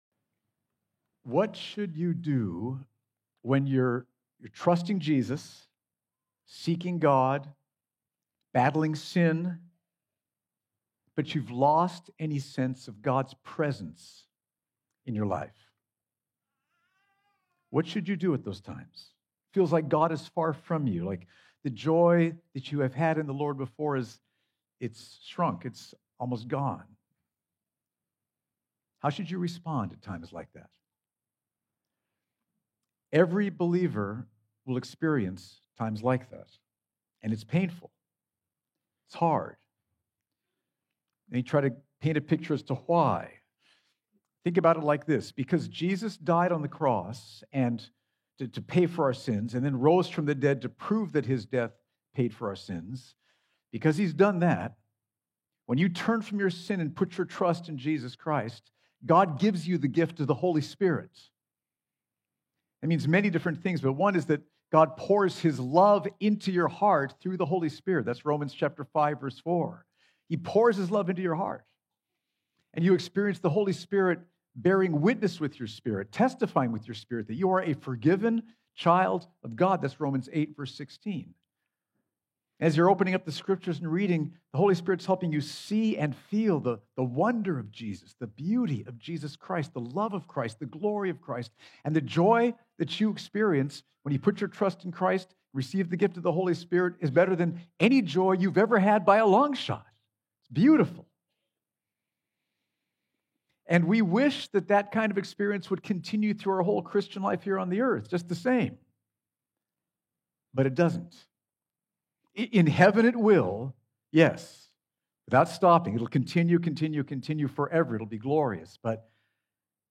sermon-mar-1-2025.mp3